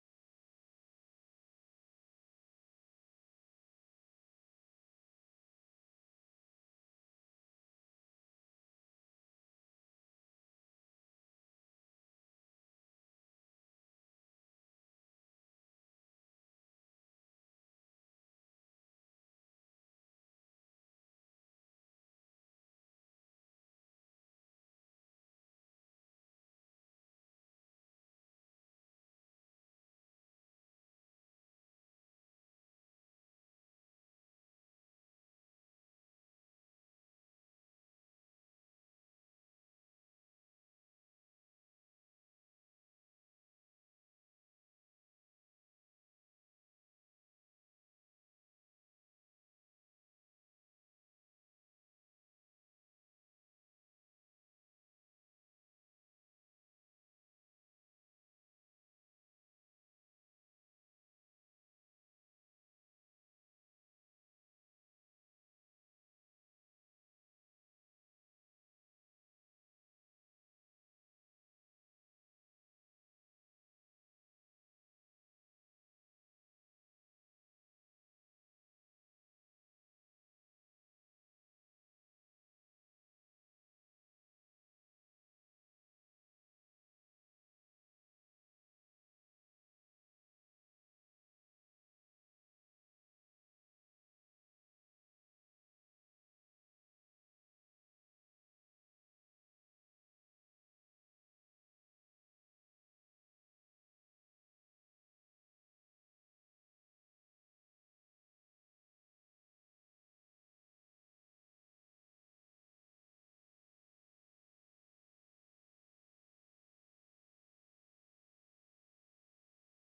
Město Varnsdorf: 2. zasedání Zastupitelstva města Varnsdorf Místo konání: Lidová zahrada, Karlova 702, Varnsdorf Doba konání: 08. prosince 2022 od 15:00 hod. 1.